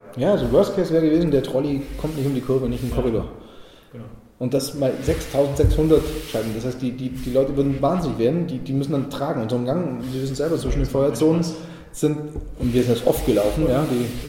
Weitere Teile der Serie "Interview mit den Innenausbau-Spezialisten